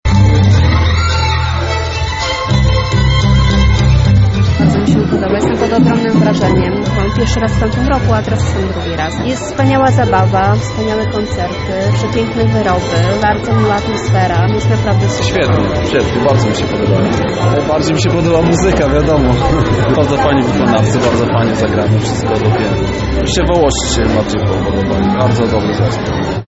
O swoich wrażeniach mówią sami widzowie.